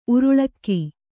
♪ uruḷakki